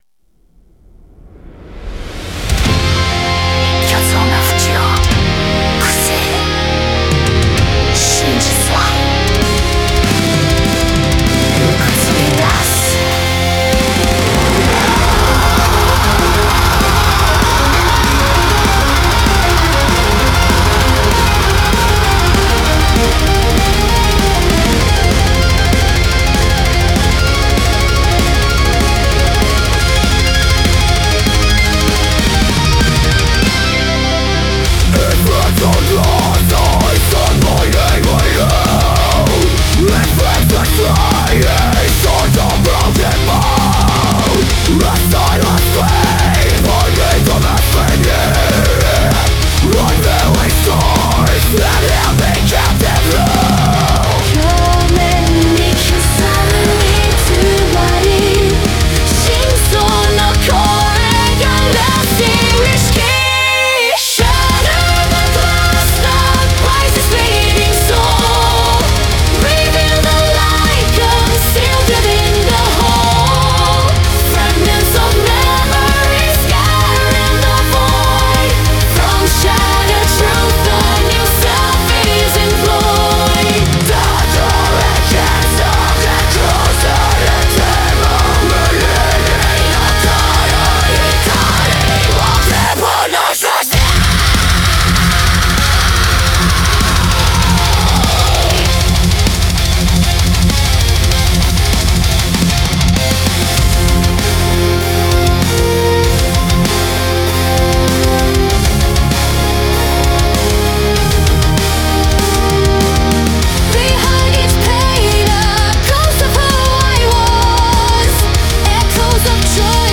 Melodic Death Metal